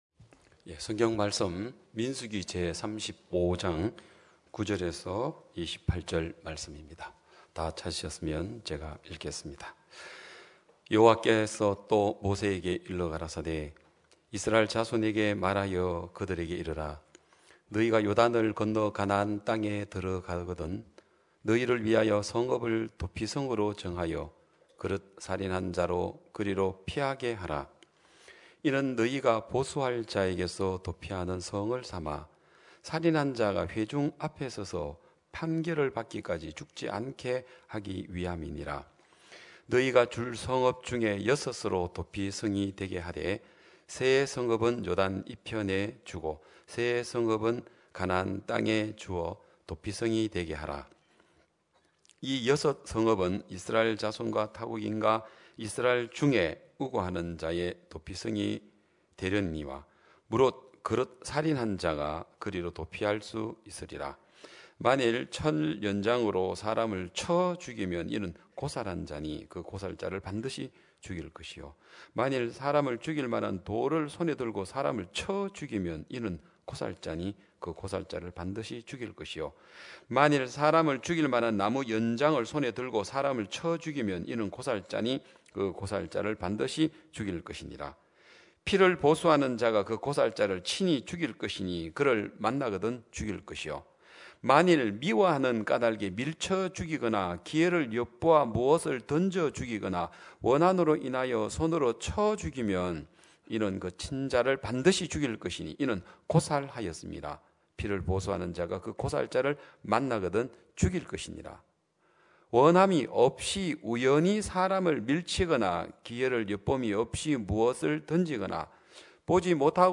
2022년 1월 23일 기쁜소식양천교회 주일오전예배
성도들이 모두 교회에 모여 말씀을 듣는 주일 예배의 설교는, 한 주간 우리 마음을 채웠던 생각을 내려두고 하나님의 말씀으로 가득 채우는 시간입니다.